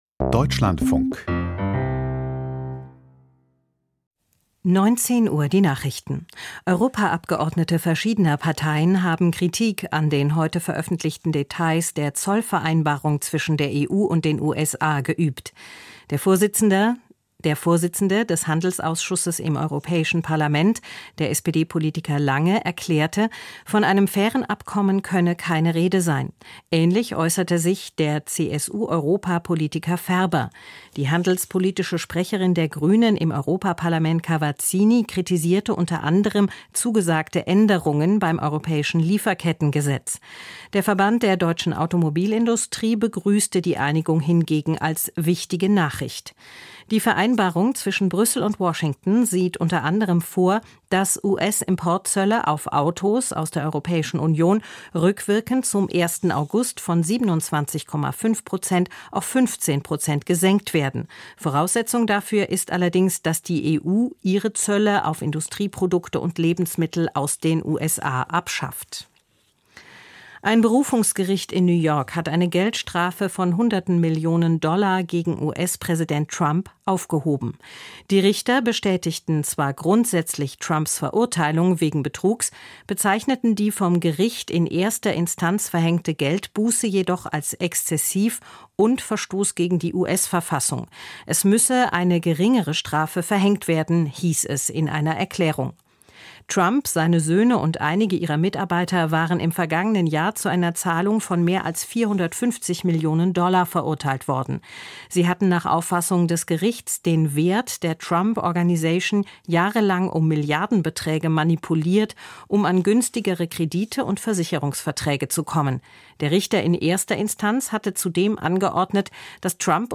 Die Nachrichten vom 21.08.2025, 19:00 Uhr